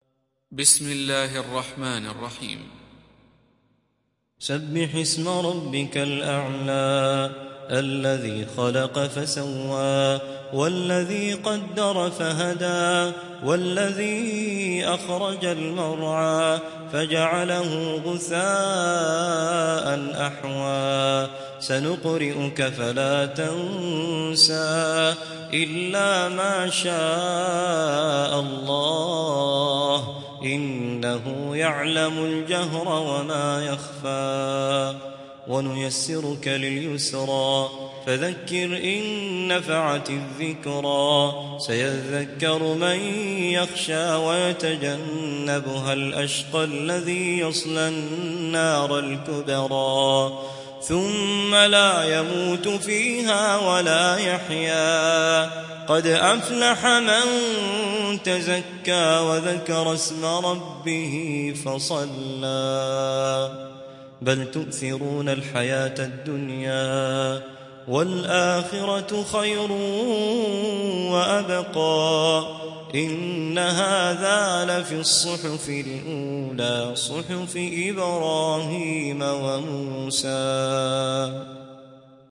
تحميل سورة الأعلى mp3 بصوت توفيق الصايغ برواية حفص عن عاصم, تحميل استماع القرآن الكريم على الجوال mp3 كاملا بروابط مباشرة وسريعة